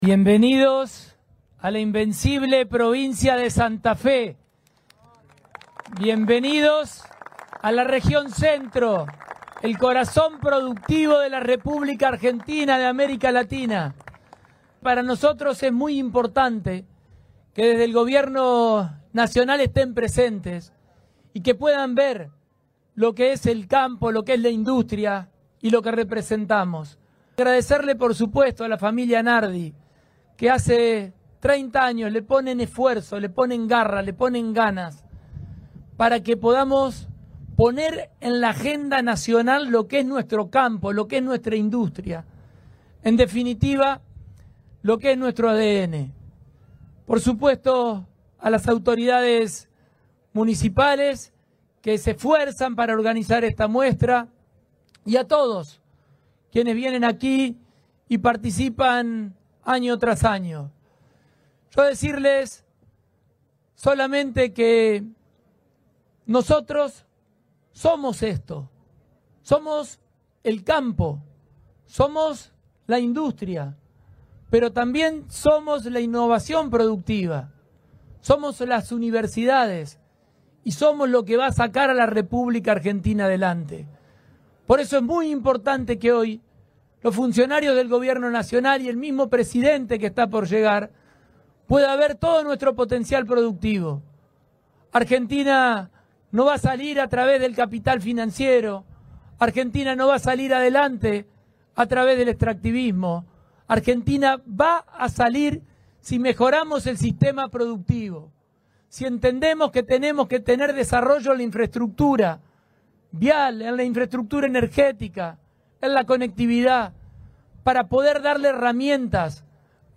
El gobernador Maximiliano Pullaro, en el acto inaugural de una nueva edición de Agroactiva.